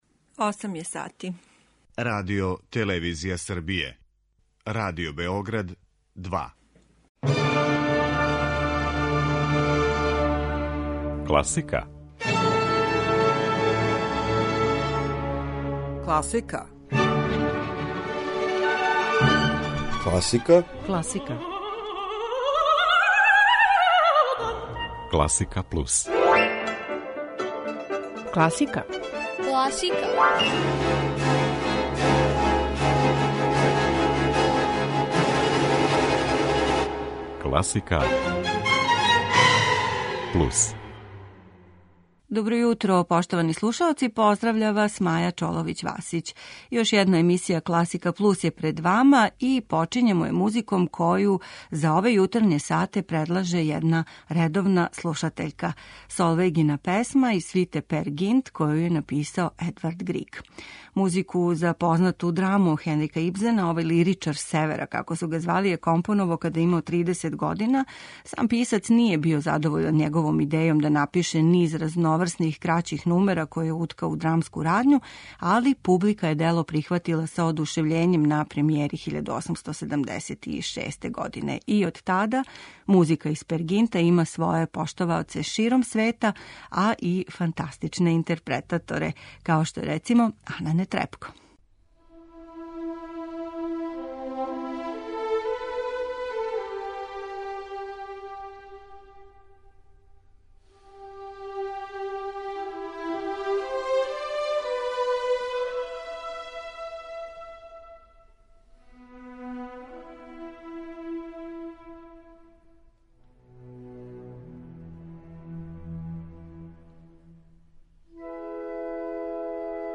Још једно јутро започињемо изабраном класичном музиком.